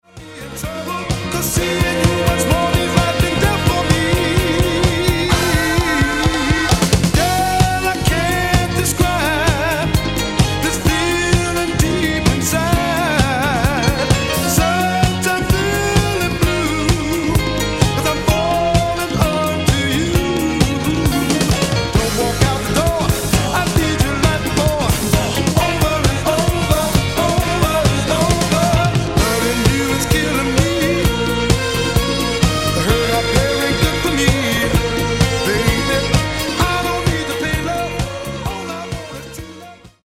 Genere:   Disco | Soul
gruppo esclusivamente di voci maschili